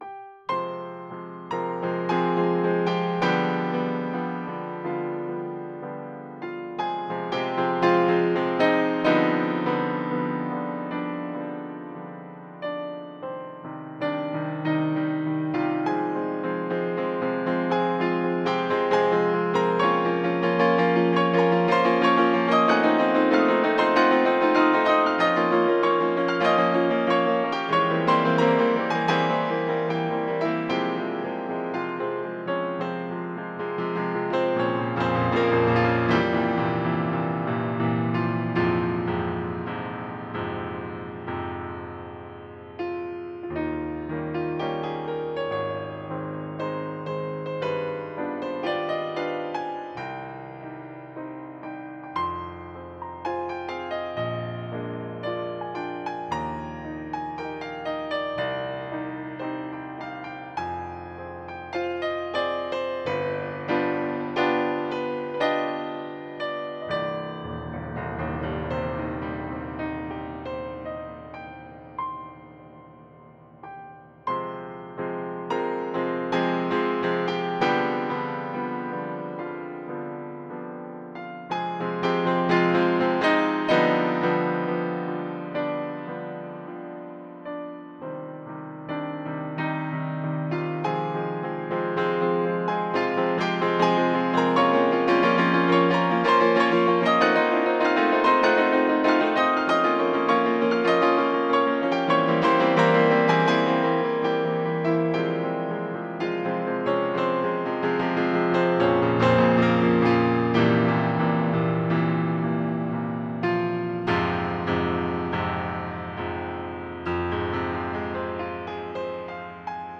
Mein Haupt-Hobby, Klavierkompositionen: